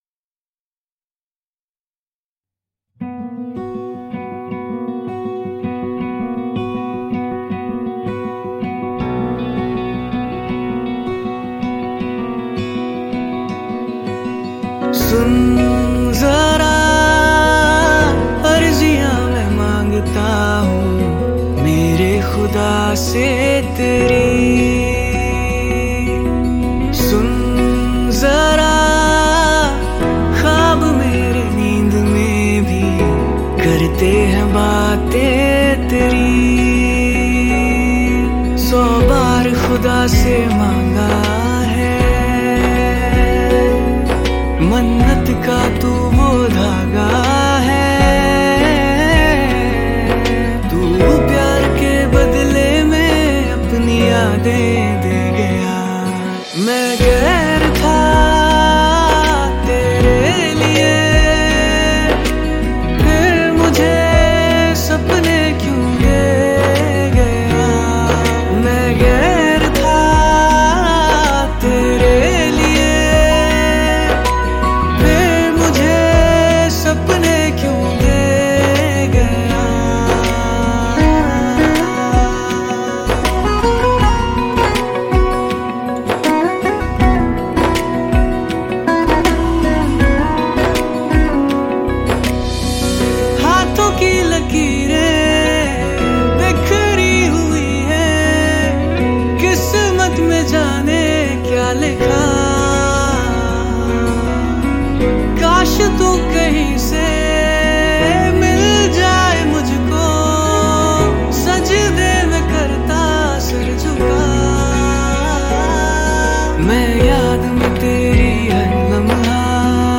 2020 Pop Mp3 Songs